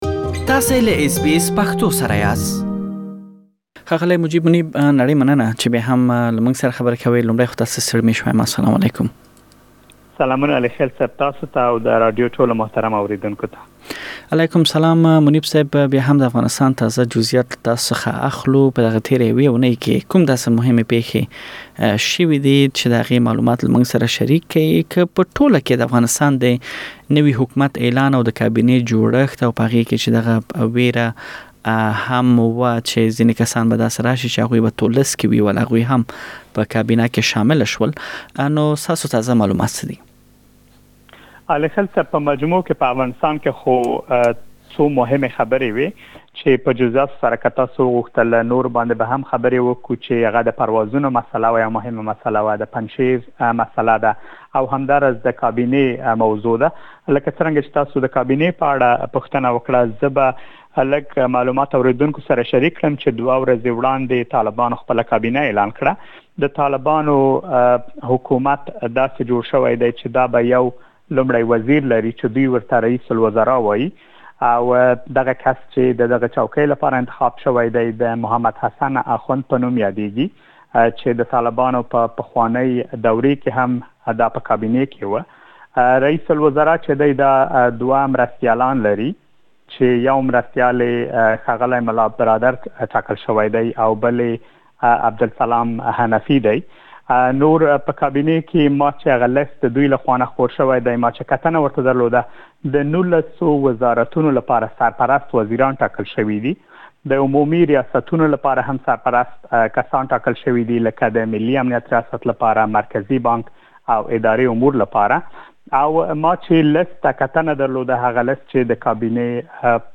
تېره اوونۍ په افغانستان کې یو زیات شمېر مهمې پیښې رامنځ ته شوي، د اوونۍ د مهمو پېښو په اړه مهم معلومات په دغه رپوټ کې اوریدلی شئ.